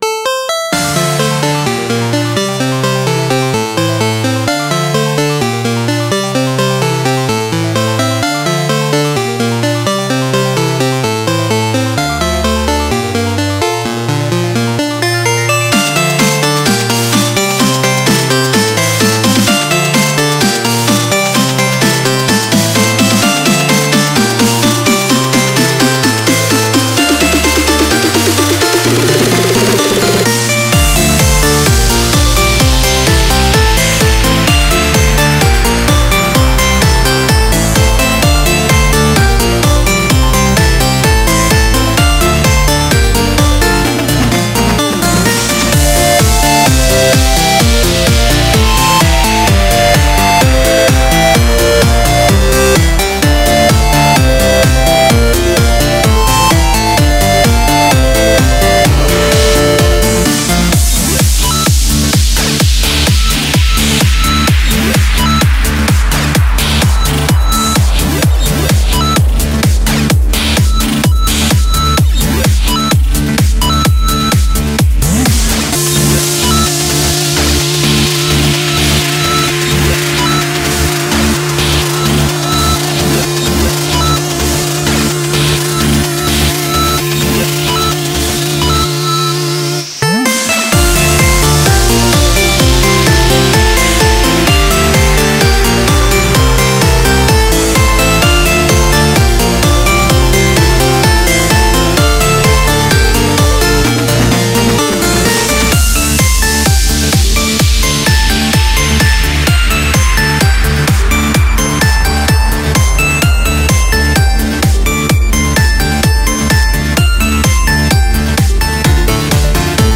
BPM128
Comments[ELECTRO-TECH]